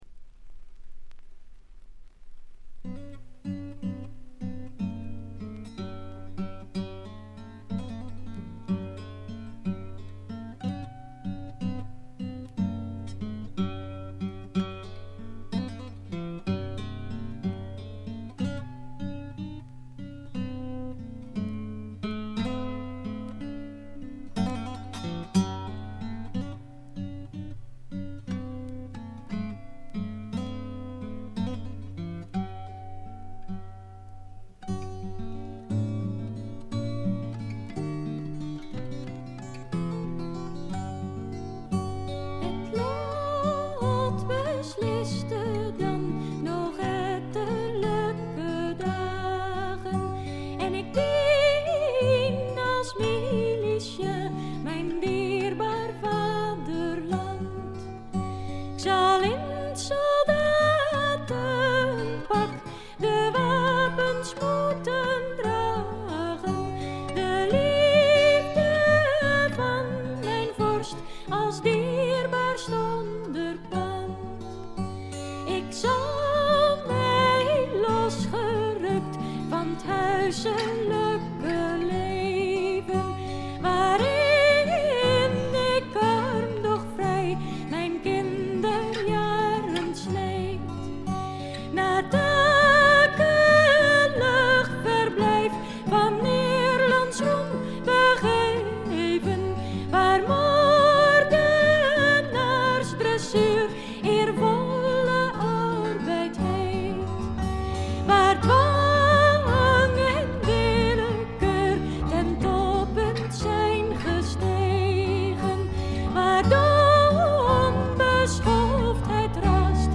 女性ヴォーカルを擁した5人組。
試聴曲は現品からの取り込み音源です。
Recorded At - Farmsound Studio